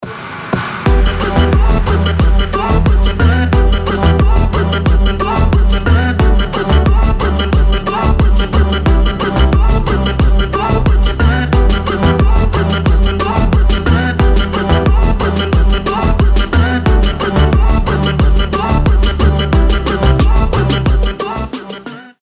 • Качество: 320, Stereo
dance
Electronic
EDM
Moombahton